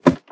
ladder2.ogg